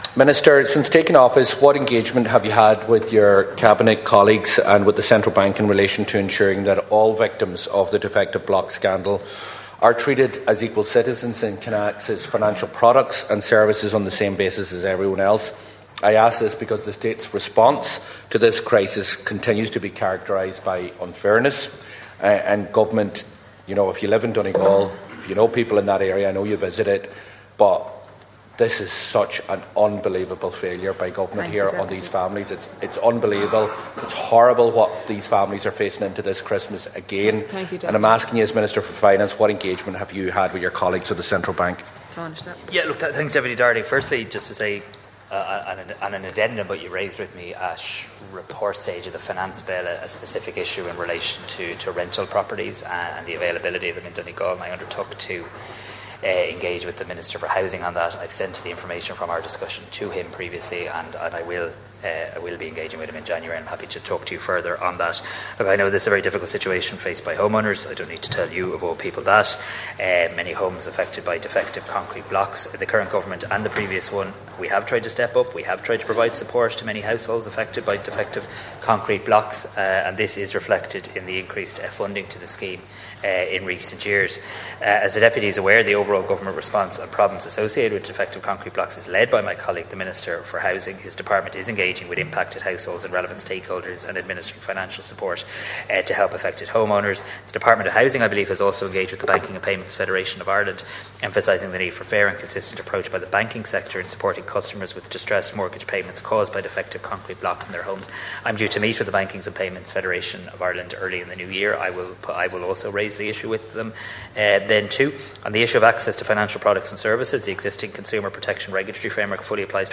Mr Harris was responding to Donegal Deputy Pearse Doherty during Finance Minister’s questions shortly before the Dail broke up for Christmas last night.